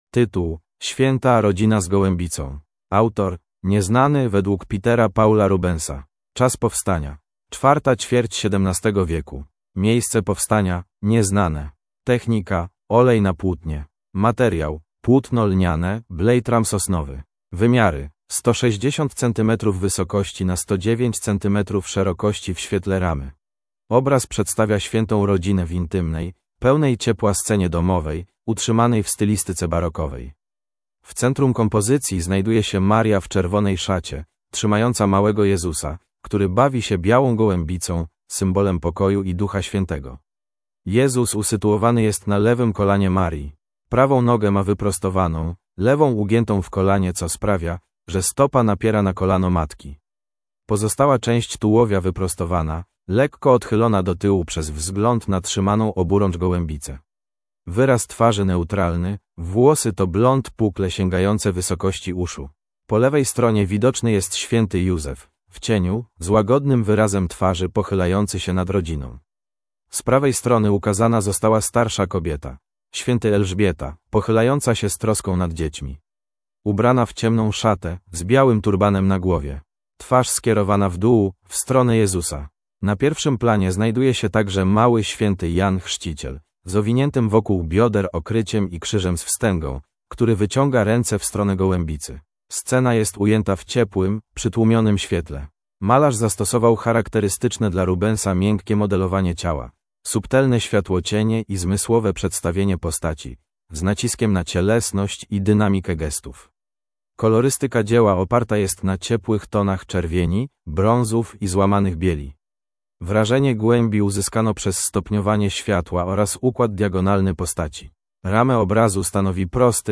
MNWr_audiodeskr_Swieta_Rodzina_z_golebica.mp3